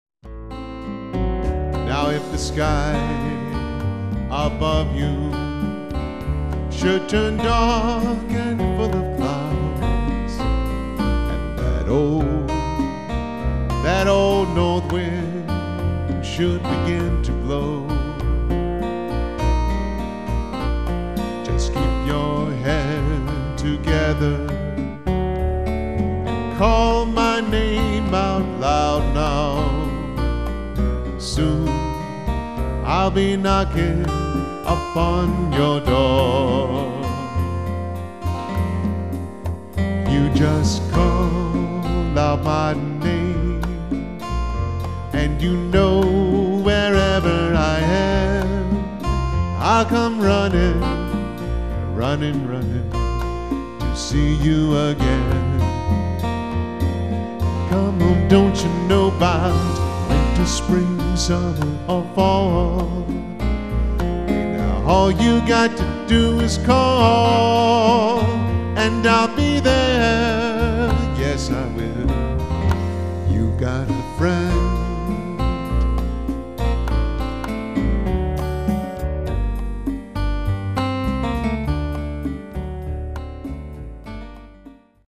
band/vocal